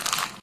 paper_scrunch_4.ogg